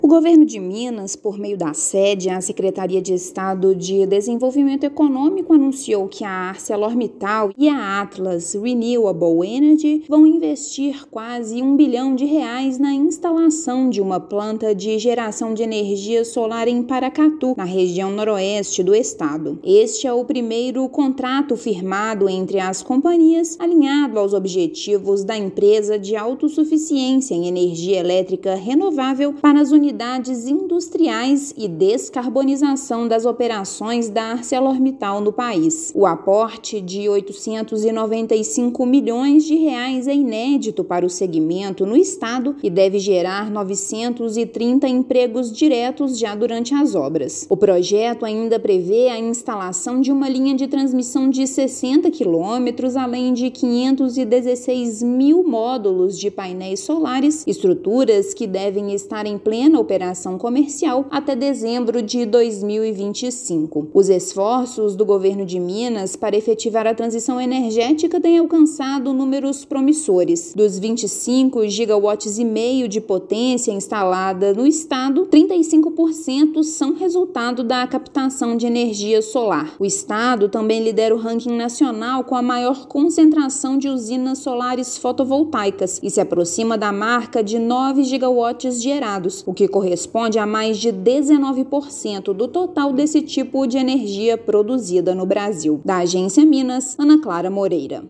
Implantação de novo projeto da siderúrgica prevê geração de 930 empregos diretos durante as obras. Ouça matéria de rádio.